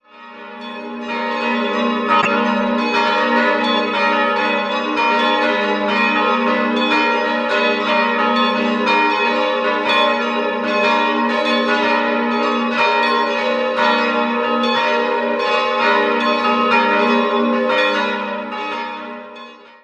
Erhalten hat sich auch das überaus wertvolle Geläute, das auf beide Türme verteilt ist. 5-stimmiges Geläute: a'-b'-h'-a''-h'' Eine genaue Glockenbeschreibung folgt im Begleittext des Youtube-Videos (siehe unten).